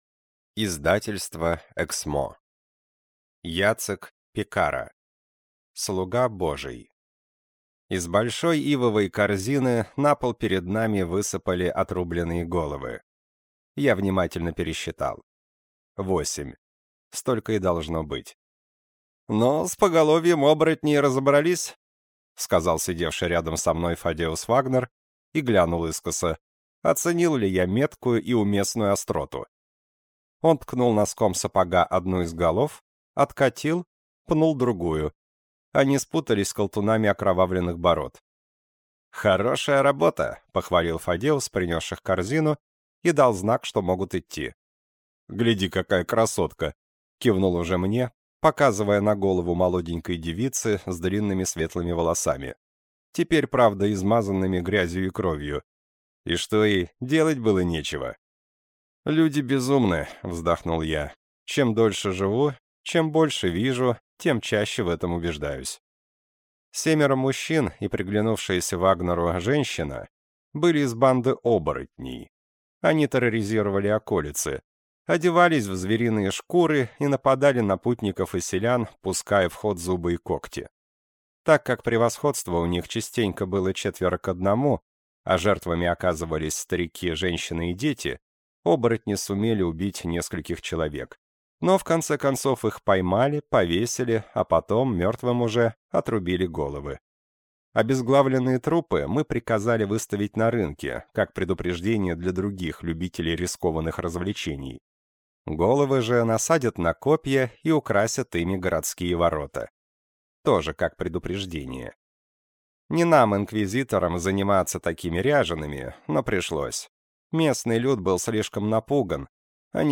Аудиокнига Слуга Божий | Библиотека аудиокниг